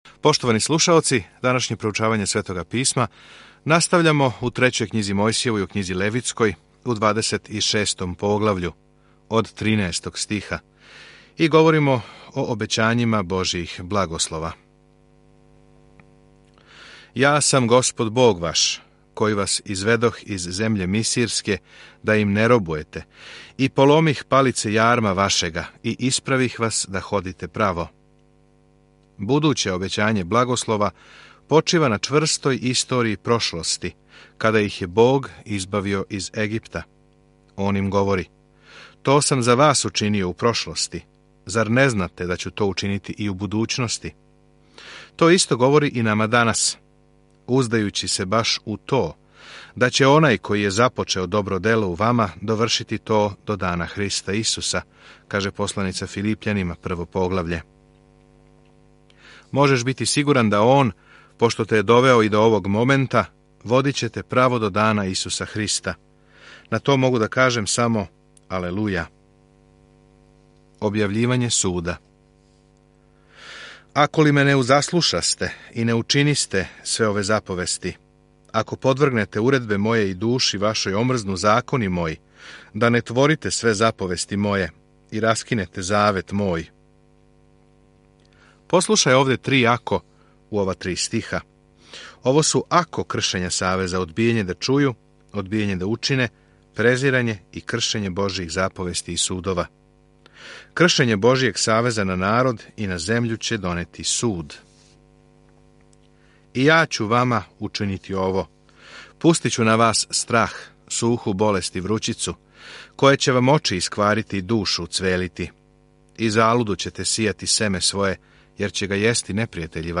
У обожавању, жртвовању и поштовању, Левитски законик одговара на то питање за древни Израел. Свакодневно путујте кроз Левитски законик док слушате аудио студију и читате одабране стихове из Божје речи.